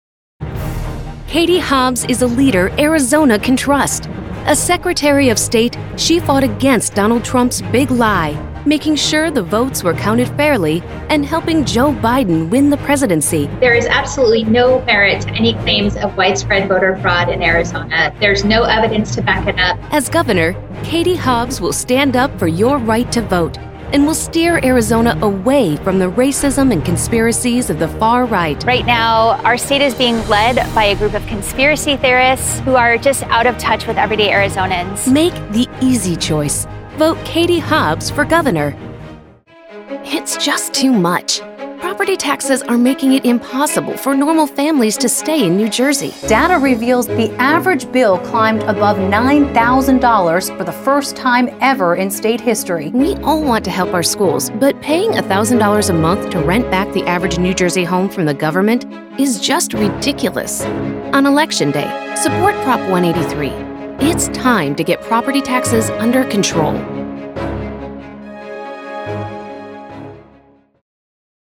Female
English (North American)
Yng Adult (18-29), Adult (30-50)
Political Sample Demo